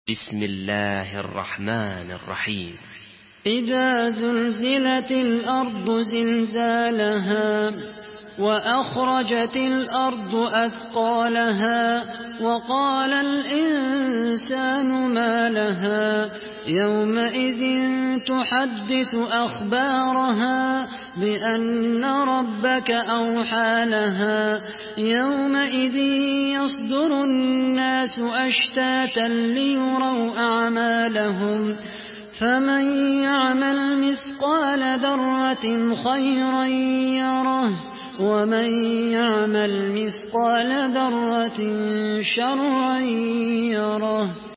Surah Zelzele MP3 by Khaled Al Qahtani in Hafs An Asim narration.
Murattal Hafs An Asim